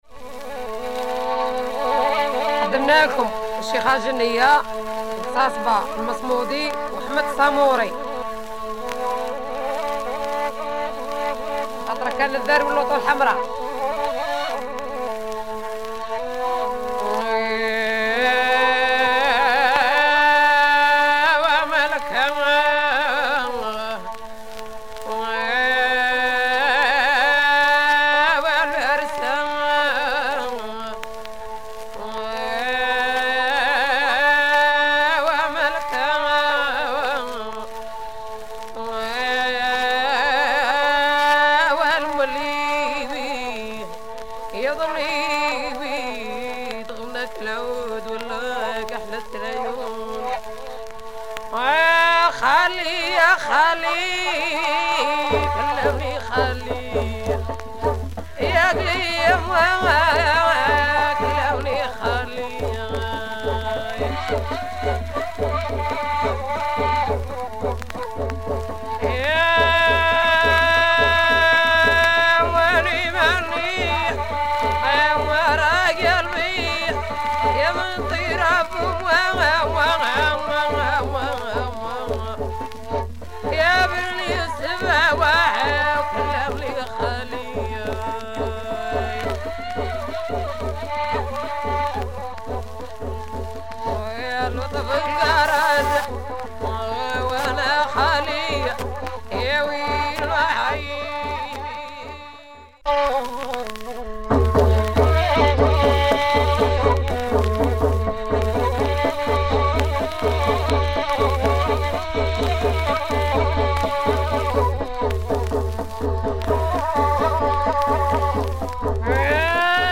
Proto rai with female chants.